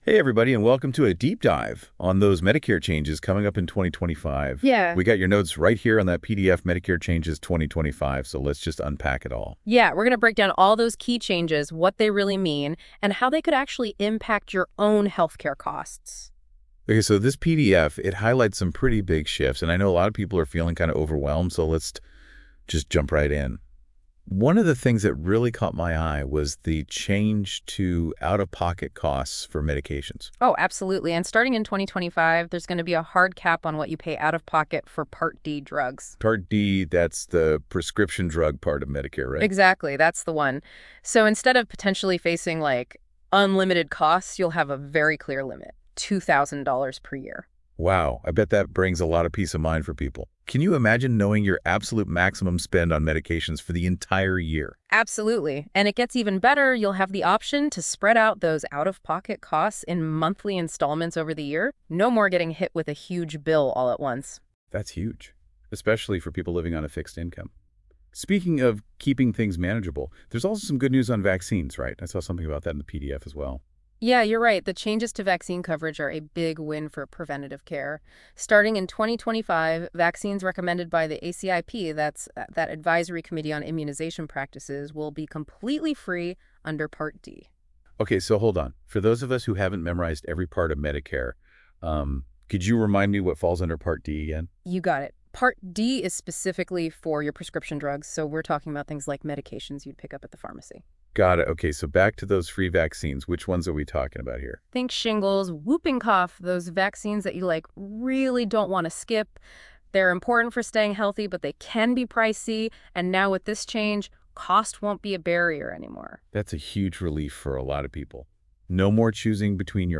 This podcast discussion dives into what you need to know to navigate Medicare in 2025. Whether you’re approaching Medicare eligibility, already enrolled, or just trying to stay ahead of the curve, we’ll explore the latest updates, new plan options, and strategies to make sure you’re getting the best coverage for your needs.